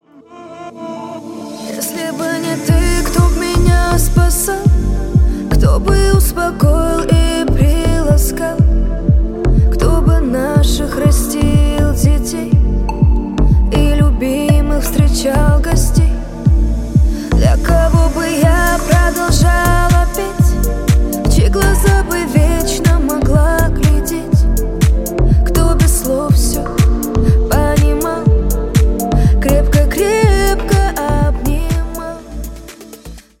• Качество: 128, Stereo
поп
красивый женский вокал